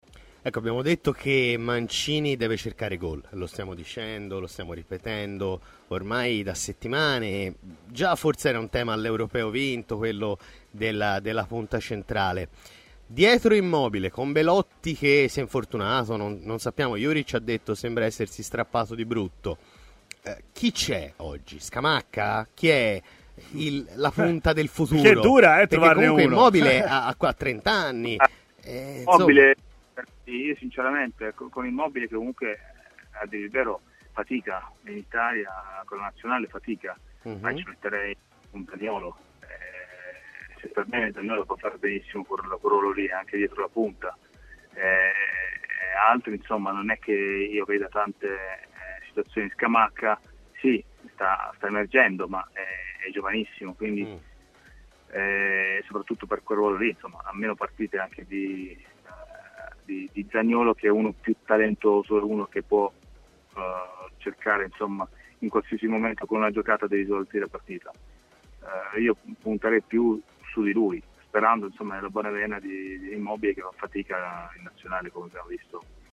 Fonte: TMW Radio